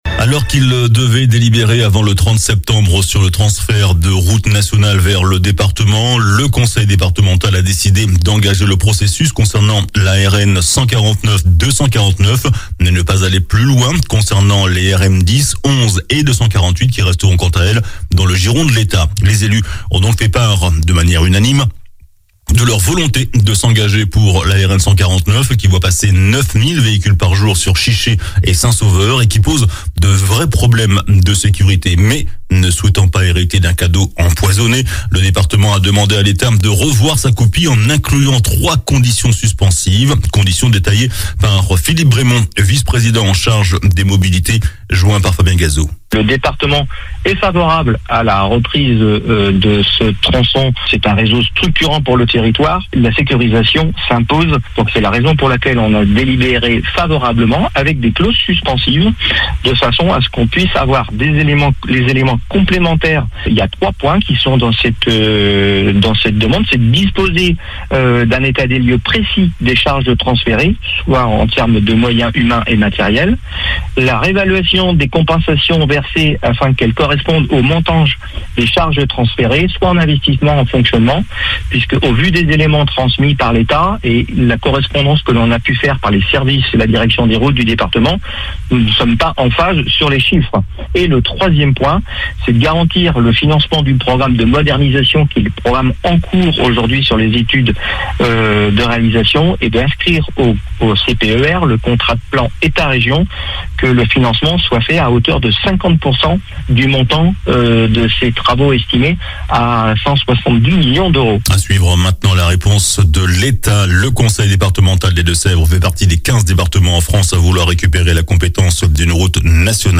JOURNAL DU MERCREDI 28 SEPTEMBRE ( SOIR )